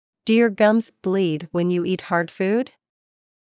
ﾄﾞｩｰ ﾕｱ ｶﾞﾑｽﾞ ﾌﾞﾘｰﾄﾞ ｳｪﾝ ﾕｰ ｲｰﾄ ﾊｰﾄﾞ ﾌｰﾄﾞ